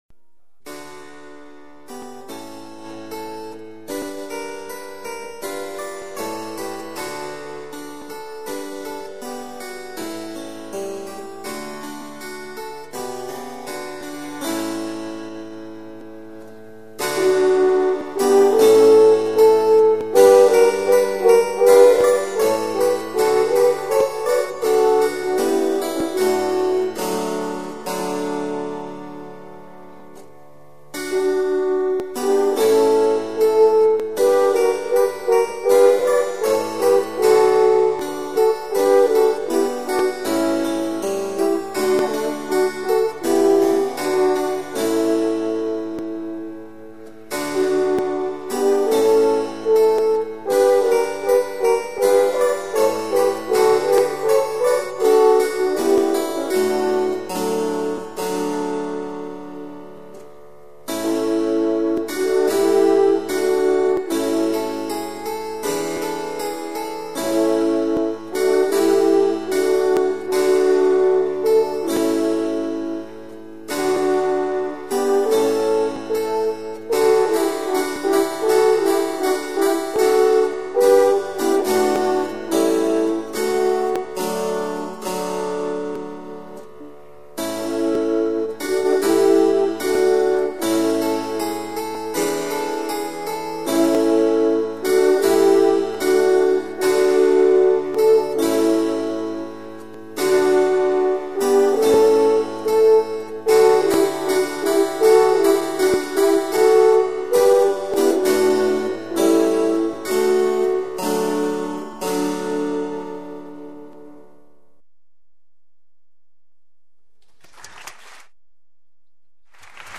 lau kitarra eta orkestrarako